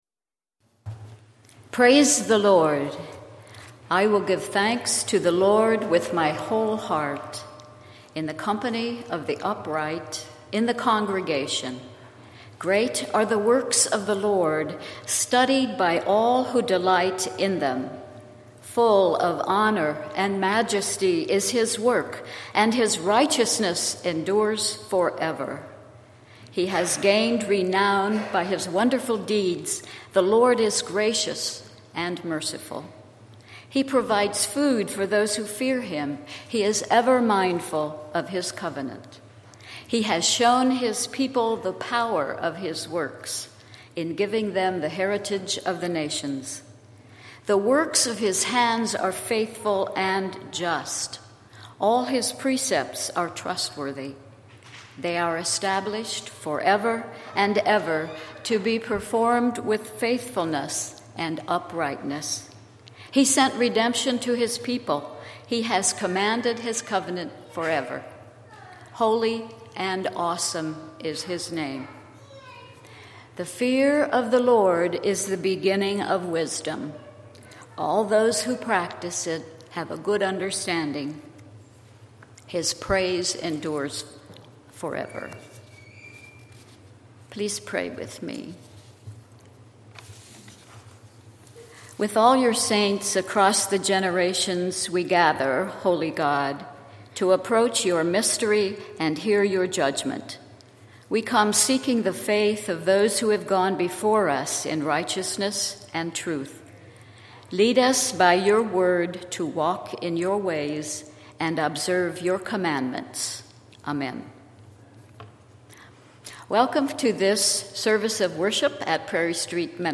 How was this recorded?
Works, signs and wonders: taking a bite out of the Bread of Life - Prairie Street Mennonite Church